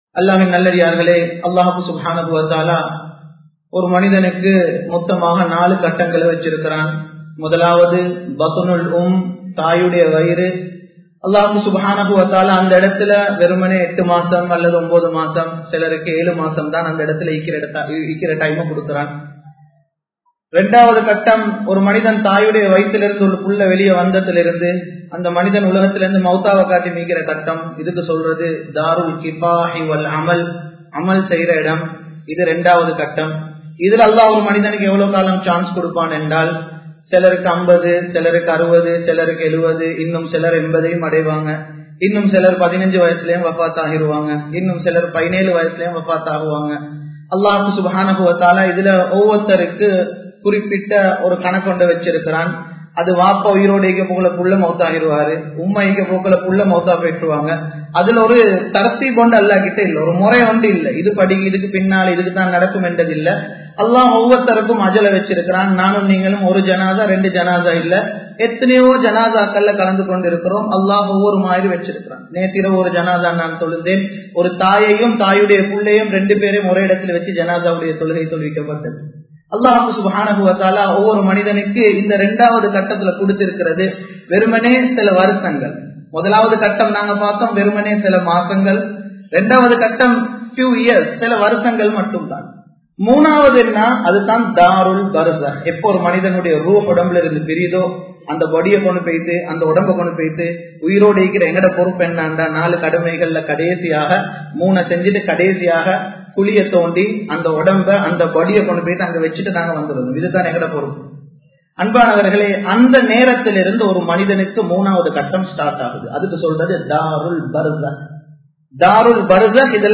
ஏன் தொழுகை அவசியம்? | Audio Bayans | All Ceylon Muslim Youth Community | Addalaichenai
Samman Kottu Jumua Masjith (Red Masjith)